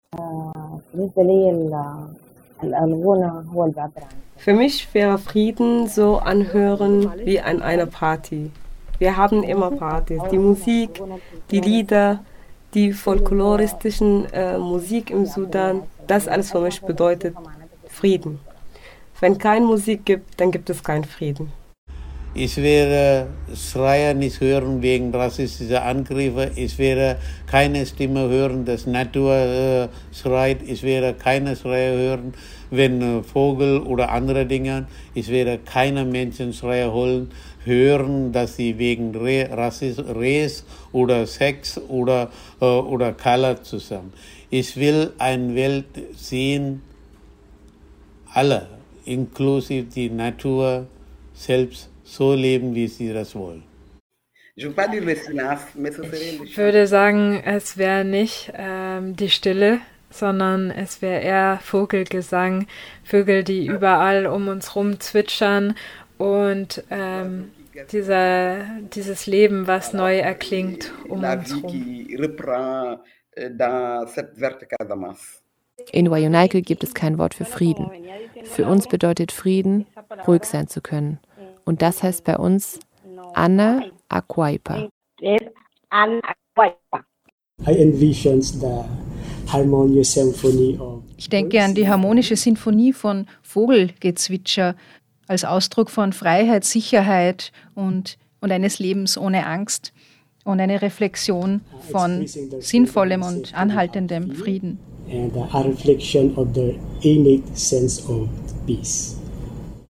Wie_klingt_Frieden_Collage.mp3